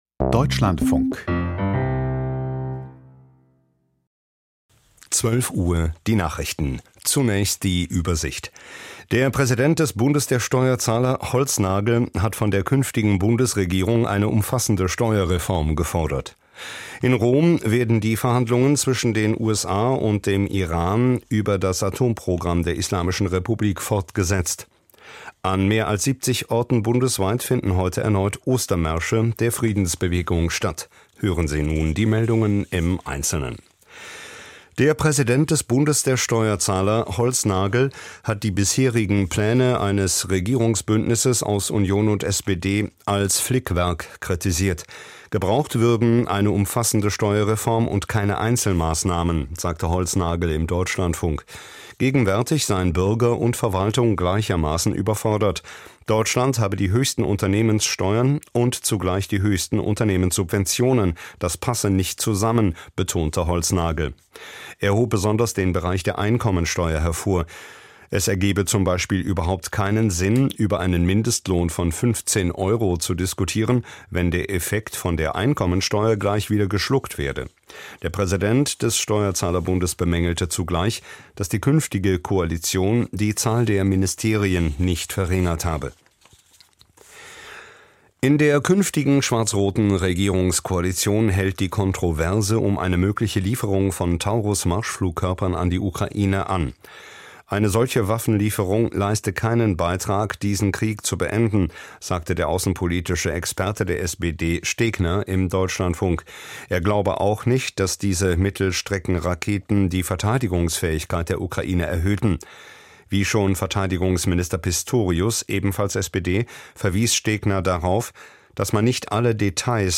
Die Deutschlandfunk-Nachrichten vom 19.04.2025, 12:00 Uhr